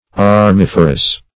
Search Result for " armiferous" : The Collaborative International Dictionary of English v.0.48: Armiferous \Ar*mif"er*ous\, a. [L. armifer; arma arms + ferre to bear.] Bearing arms or weapons.